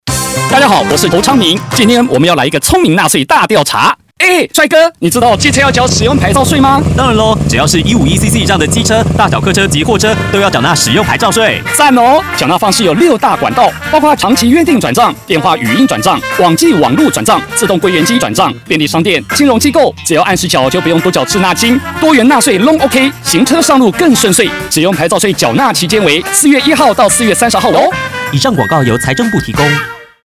上期使用牌照稅-30秒廣播廣告(國語).mp3